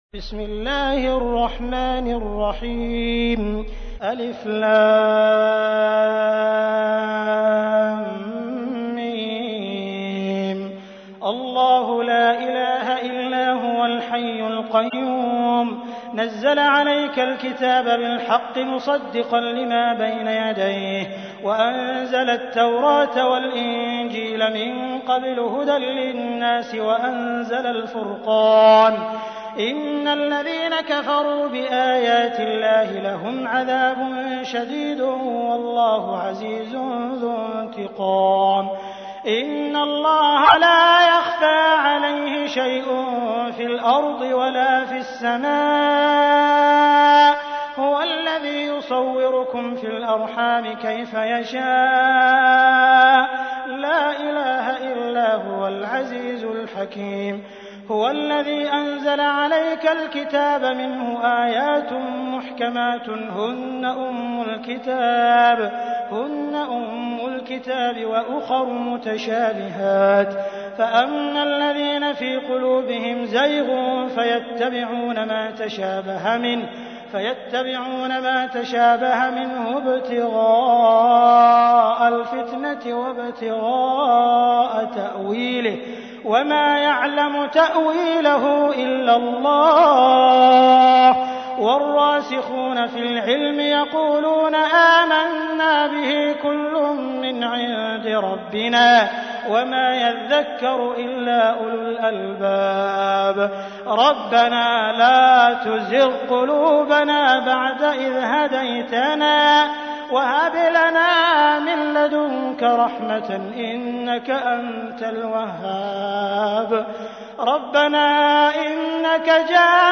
تحميل : 3. سورة آل عمران / القارئ عبد الرحمن السديس / القرآن الكريم / موقع يا حسين